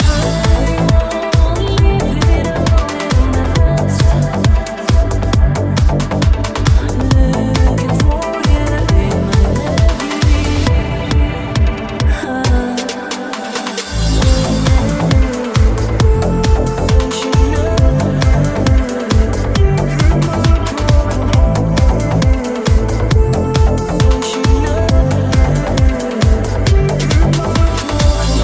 light deep house releases
Genere: deep, slap, tropical, ethno, remix